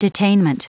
Prononciation du mot detainment en anglais (fichier audio)
Prononciation du mot : detainment
detainment.wav